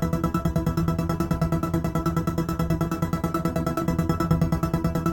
Index of /musicradar/dystopian-drone-samples/Tempo Loops/140bpm
DD_TempoDroneC_140-E.wav